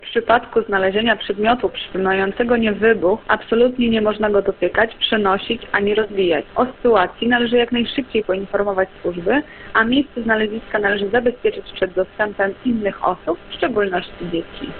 – Natychmiast zarządzono ewakuację okolicy – mówi podkom.